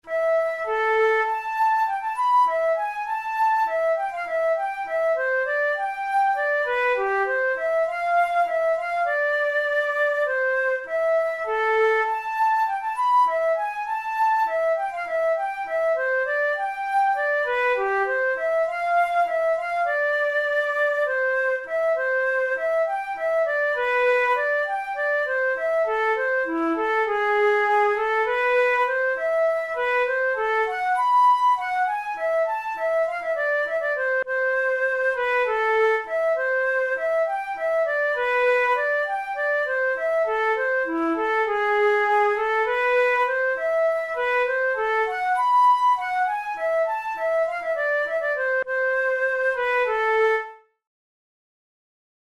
Traditional English tune
This English country dance tune was first published in 1701; it later appeared as “Up with Aily” in 1703.
Categories: Dance tunes Traditional/Folk Difficulty: easy